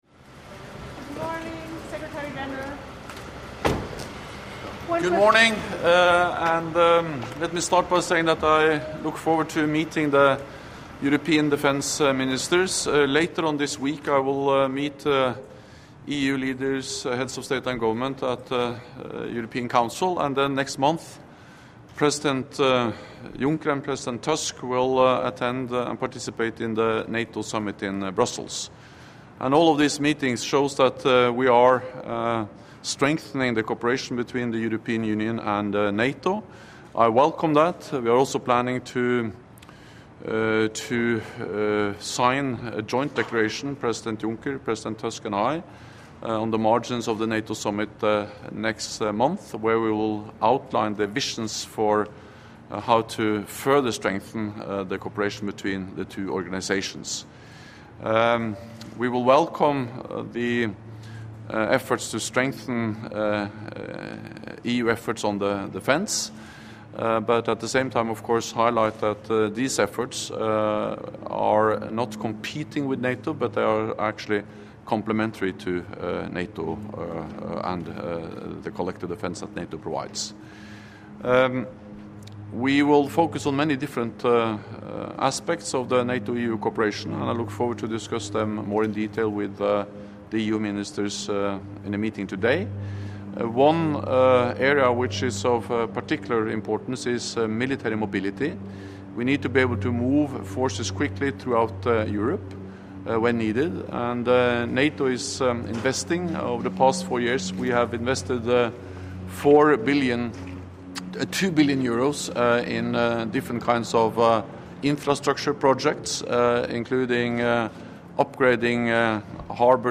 S'exprimant en prélude à une réunion avec les ministres de la Défense des pays membres de l'Union européenne ce lundi (25 juin 2018), le secrétaire général de l'OTAN, M. Jens Stoltenberg, s'est félicité du renforcement de la coopération entre l’OTAN et l’UE.